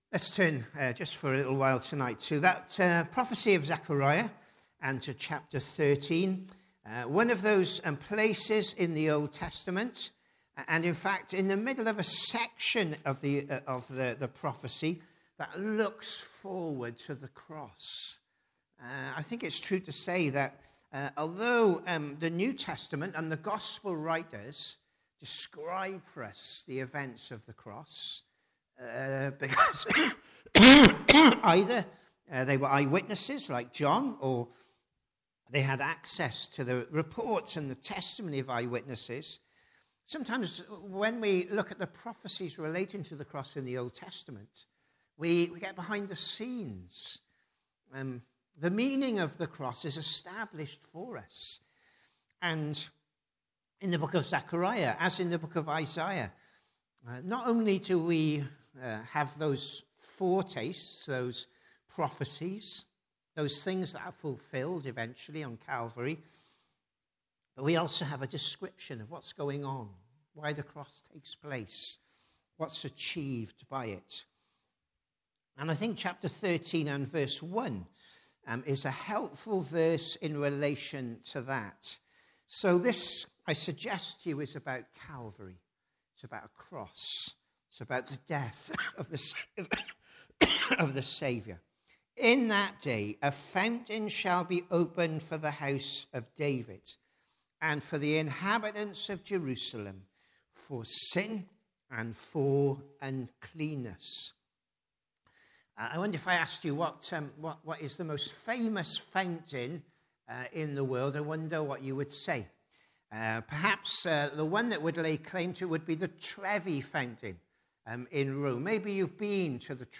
Congregation: PM Service